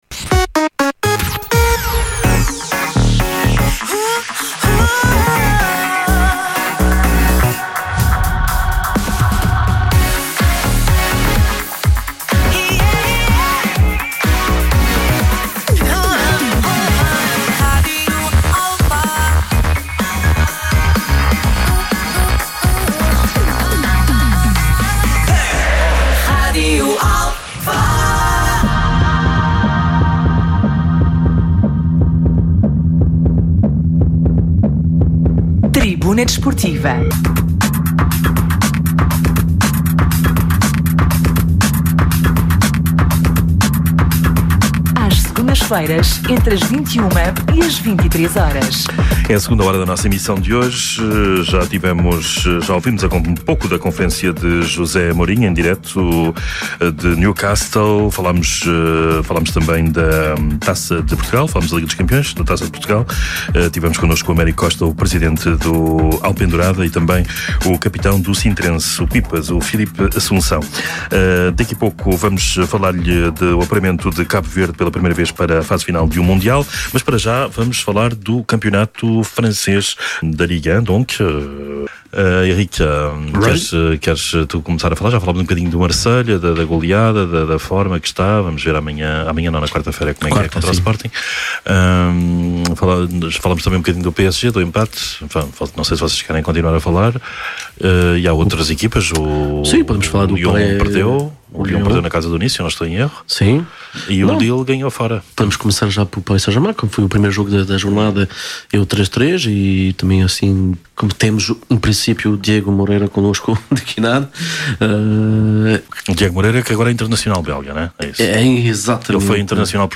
Atualidade Desportiva, Entrevistas, Comentários, Crónicas e Reportagens.
Tribuna Desportiva é um programa desportivo da Rádio Alfa às Segundas-feiras, entre as 21h e as 23h.